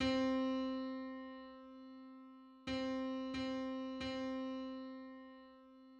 Just: 2401:2400 = 0.72 cents.
This media depicts a musical interval outside of a specific musical context.
Breedsma_on_C.mid.mp3